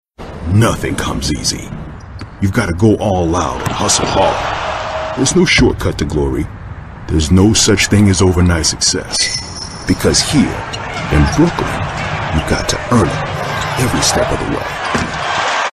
A rich, deep reverberant and soulful voice
Versatile and dynamic, his range varies from a hyped hip-hop announcer, to a smooth and elegant read.
Brooklyn attitude, biting, bravado, cocky, Deep Voice, edgy, tough